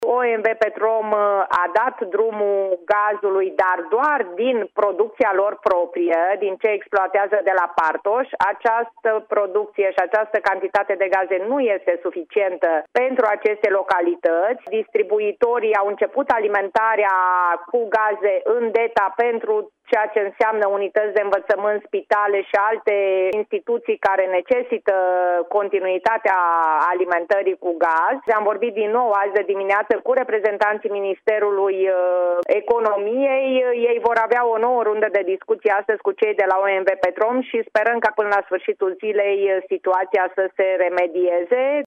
Într-o intervenție la Radio Timișoara, ea a precizat că stocul de gaz din sonda de la Partoș, operată de OMV Petrom, este aproape epuizat și că este nevoie ca operatorul privat să pună la dispoziție conducta pentru a se livra gaze din sistemul national către populație.